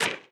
SPADE_Dig_06_mono.wav